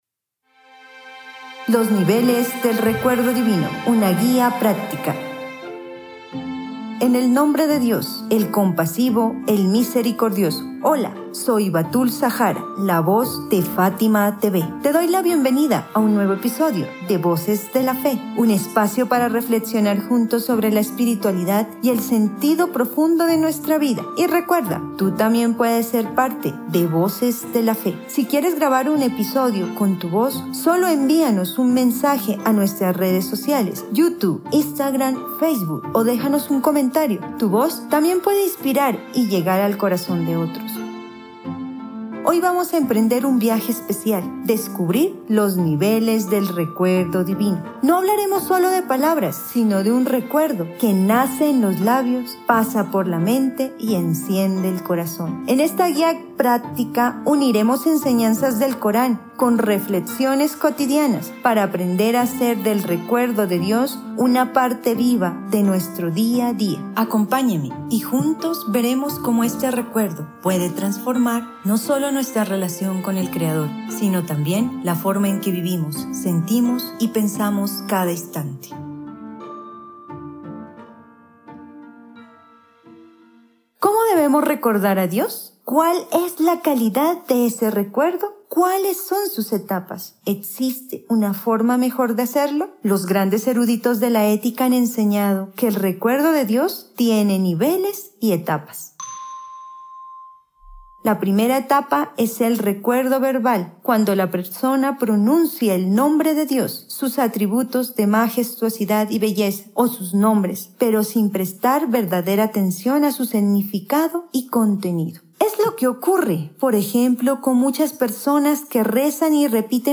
Exploraremos las etapas desde el recuerdo verbal hasta la conexión profunda del corazón con lo divino. Aprende cómo cultivar una relación más significativa con tu Creador a través del Dhikr (recuerdo) en esta lección que combina enseñanzas del Corán y reflexiones prácticas.